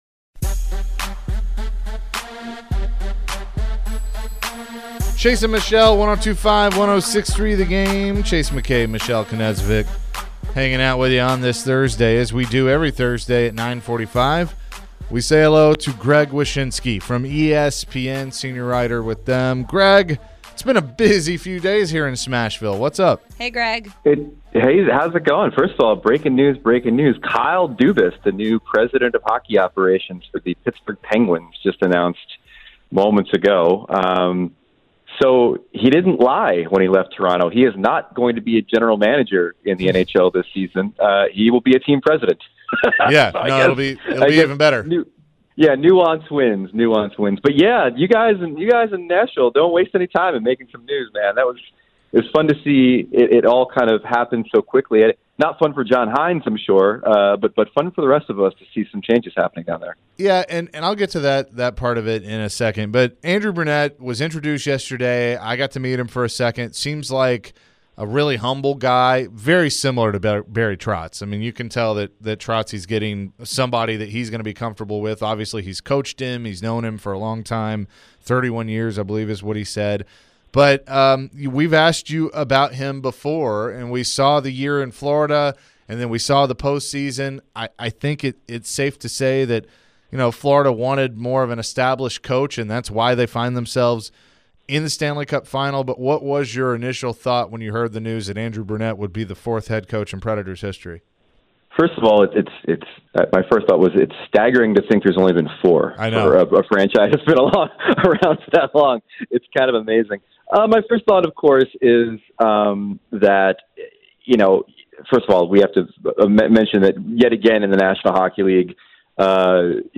Greg Wyshynski Interview (6-1-23)